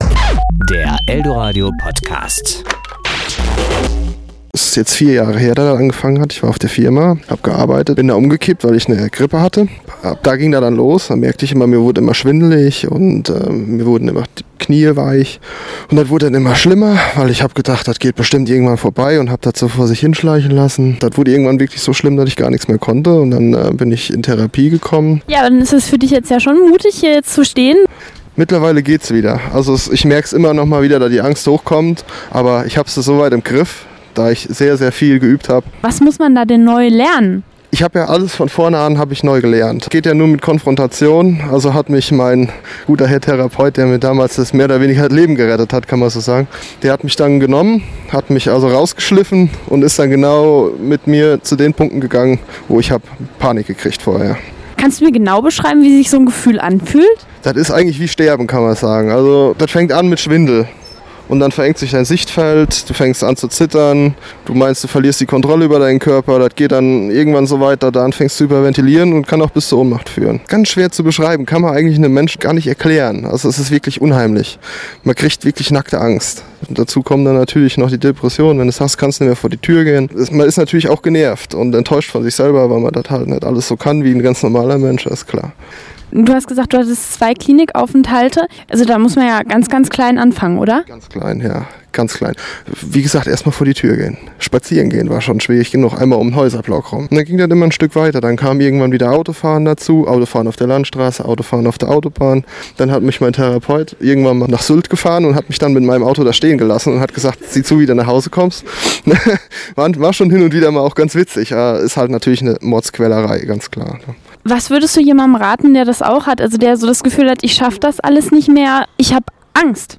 podcast_Angst Interview.mp3